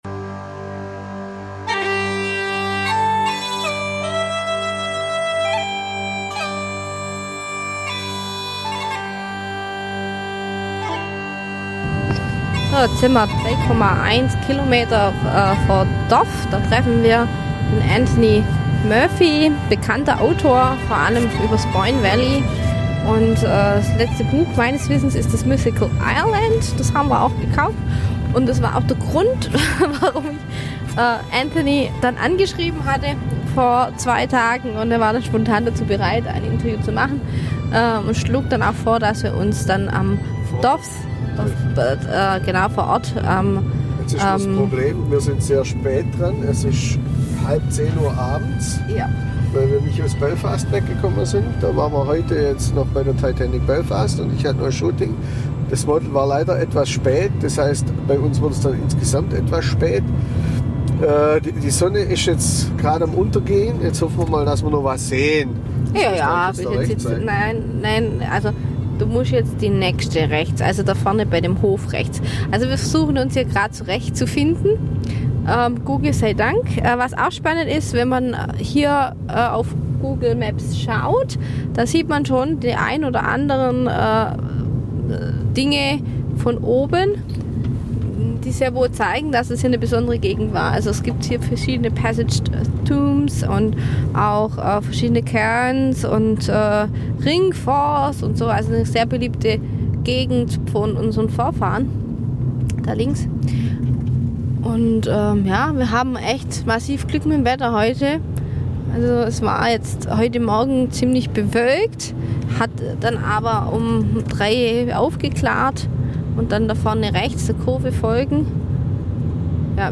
Wo Mythos und Archäologie übereinstimmen, das erklärt er uns in diesem Interview, das er uns vor Ort in Dowth im Boyne Valley.